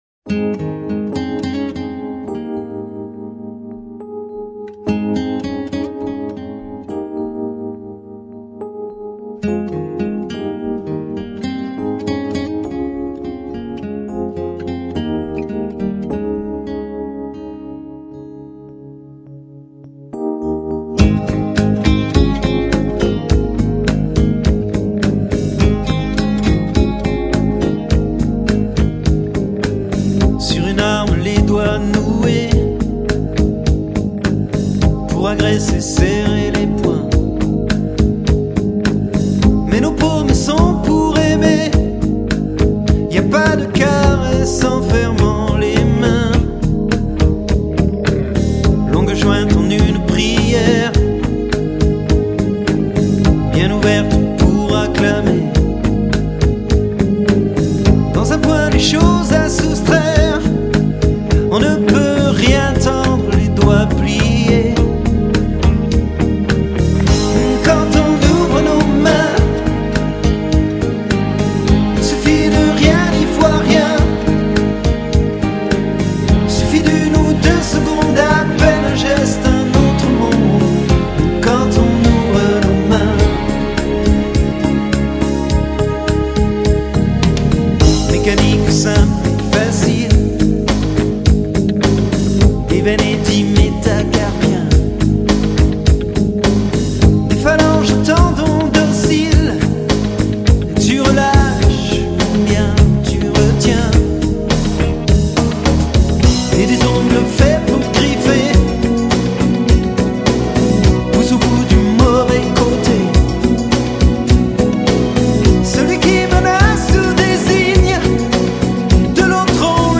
[ Messe de mariage ]